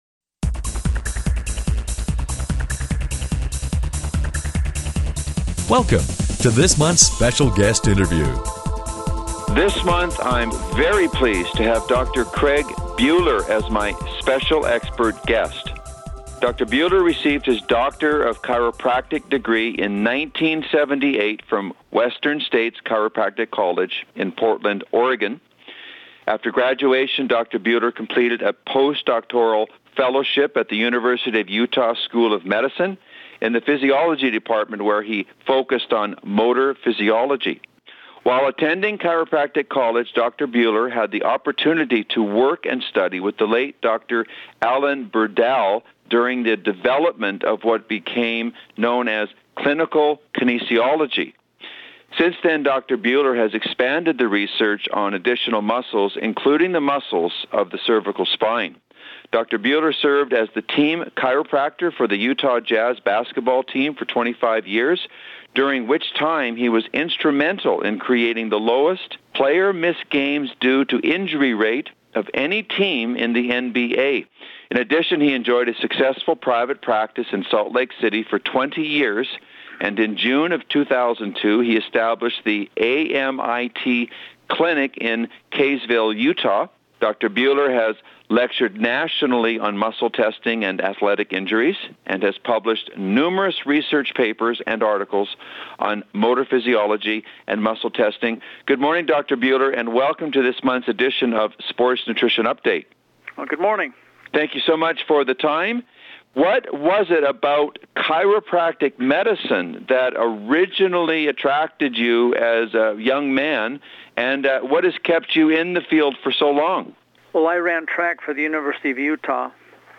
Special Guest Interview Volume 12 Number 6 V12N6c